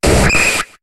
Cri de Kabuto dans Pokémon HOME.